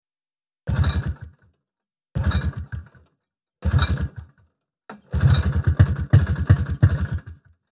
На этой странице собраны звуки снегохода: рев мотора, скрип снега под гусеницами, свист ветра на скорости.
Звук снежного мотоцикла Ямаха 1979 года, который не заводится